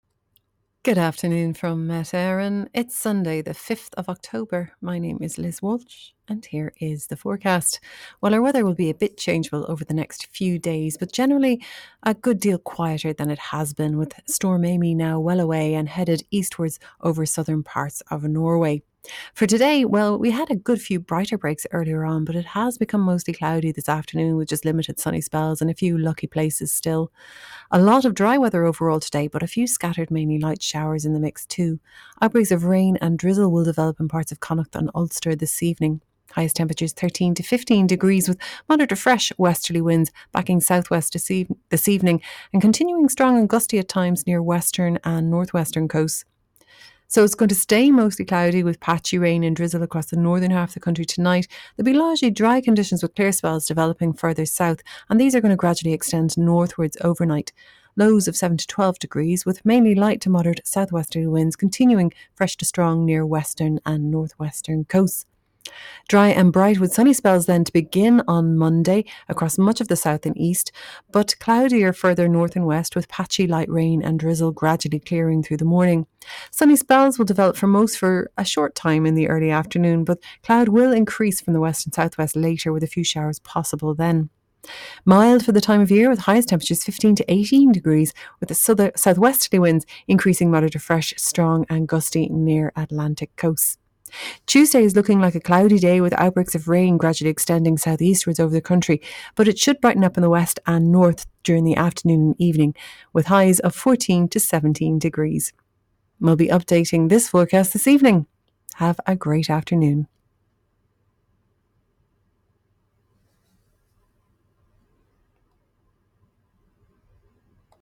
Weather Forecast from Met Éireann / Ireland's Weather 2pm Sunday 5 October 2025